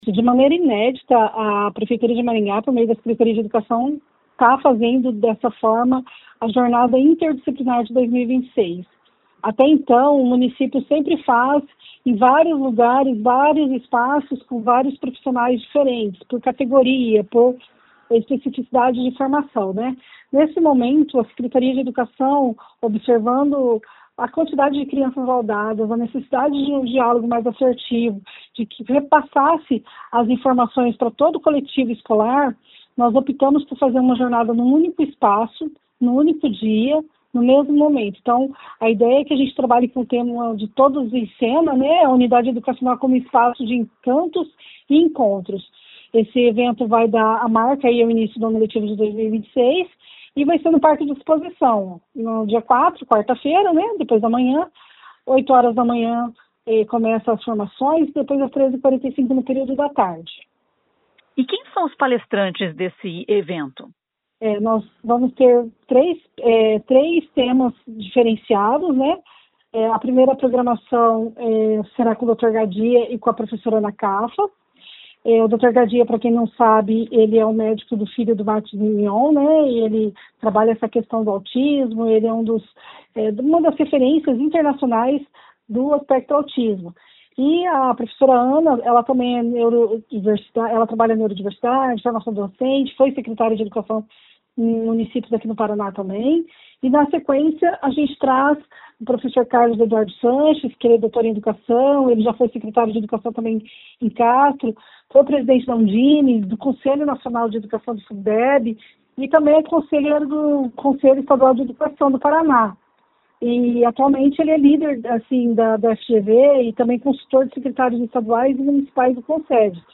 Ouça o que diz a secretária de Educação de Maringá, Adriana Palmieri: